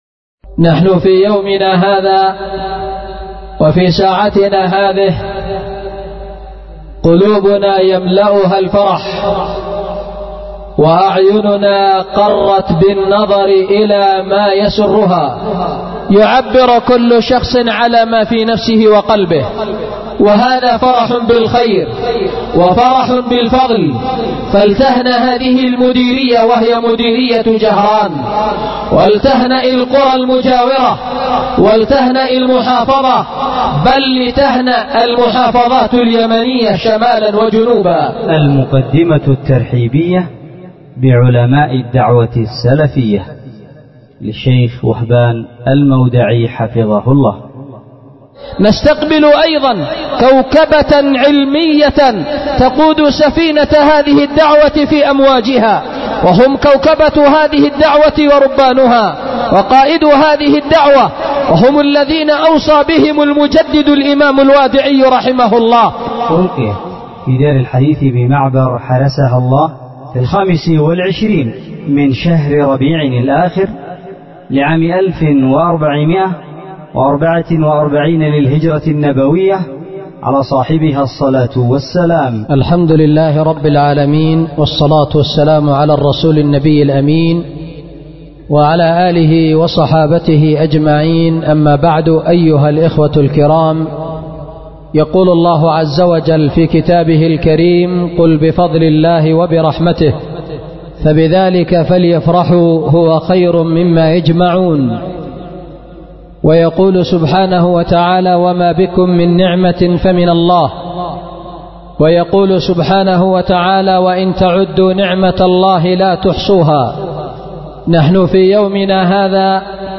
أُلقيت بدار الحديث بمعبر حرسها الله